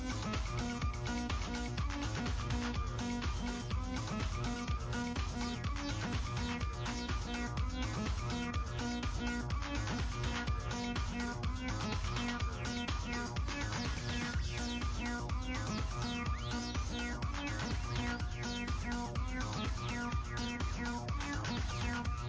Acid Trance/House track - House Party Channel 4